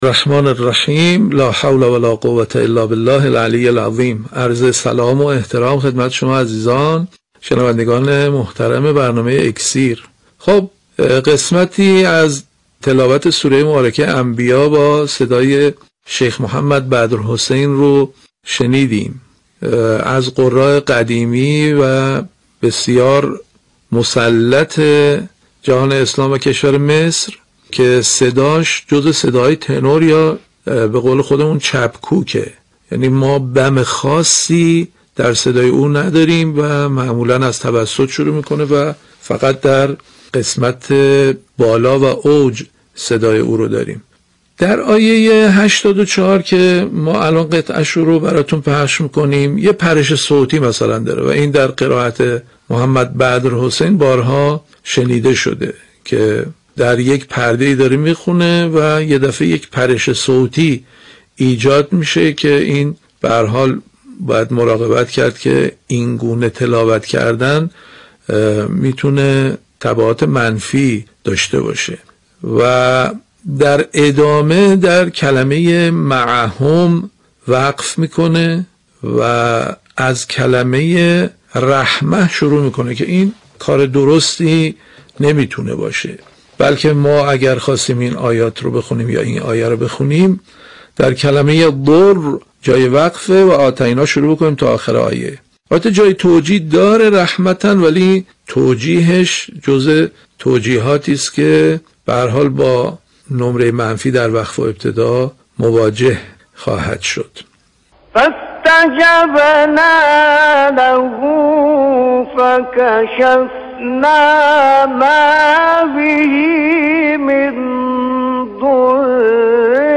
یادآوری می‌شود این تحلیل در برنامه «اکسیر» از شبکه رادیویی قرآن پخش شد.